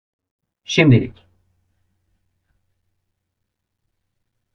/ˈʃim.di.lic/